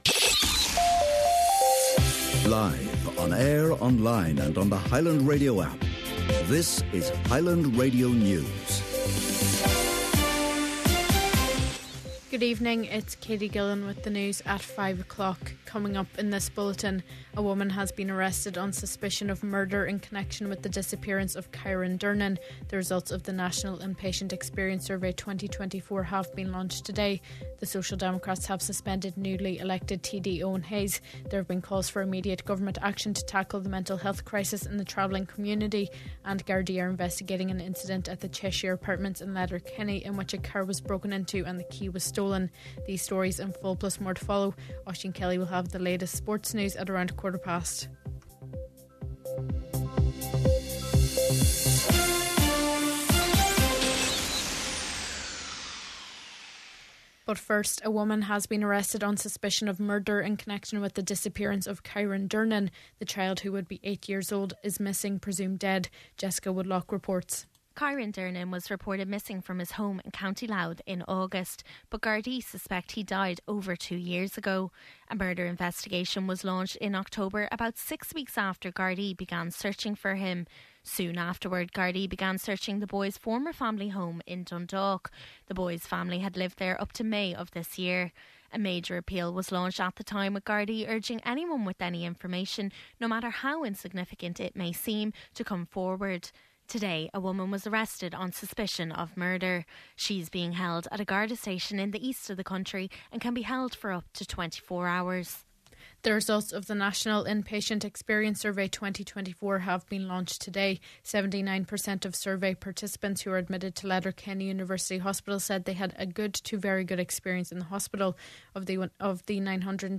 Main Evening News, Sport and Obituaries – Tuesday December 10th